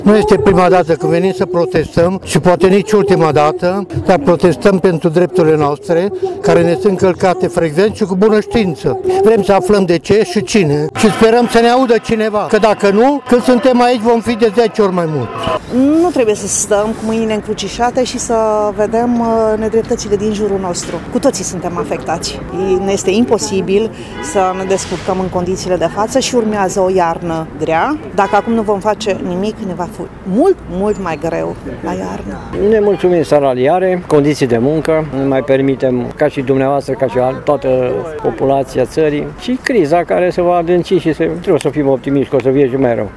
La protest participă reprezentanți ai majorității sectoarelor de activitate, de la învățământ, sănătate, transporturi, asistență socială, până la sindicate din sectorul privat.